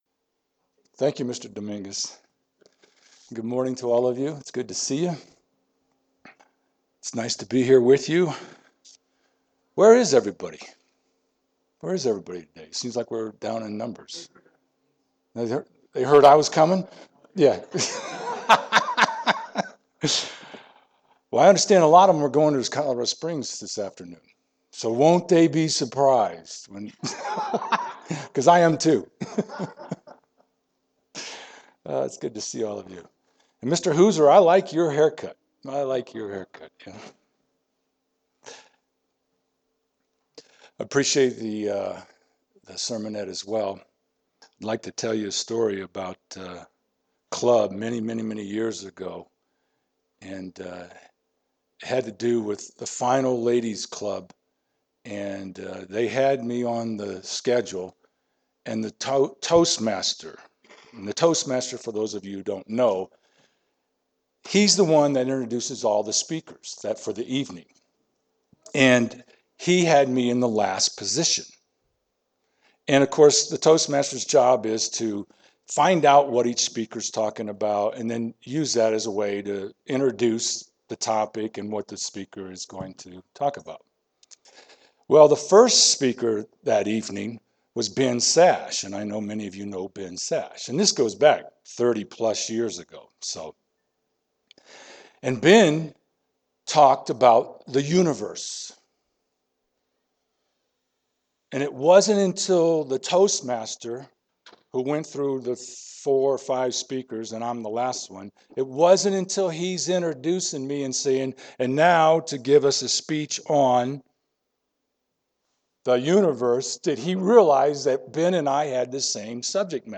This sermon examines further lessons we can learn from the Foot Washing Ceremony.
Given in Colorado Springs, CO Denver, CO